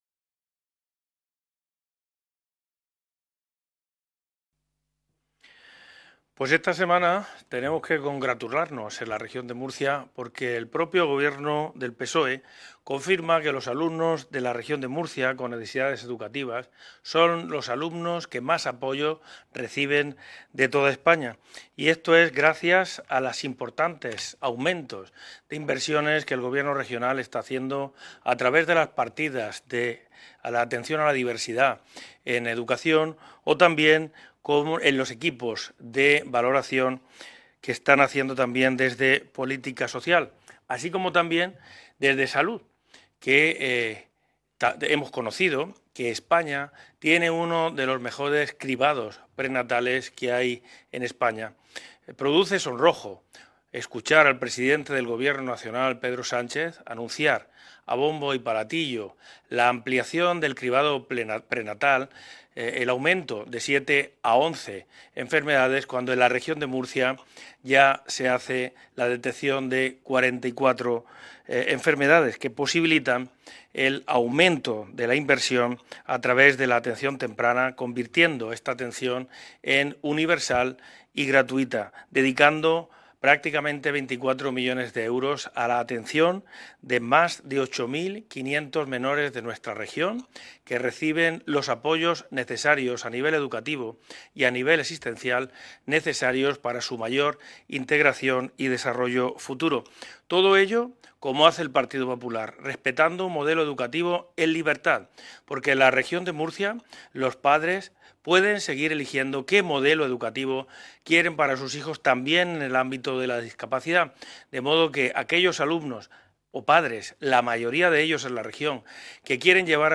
Ruedas de prensa tras la Comisión Especial de Estudio sobre Infancia y Adolescencia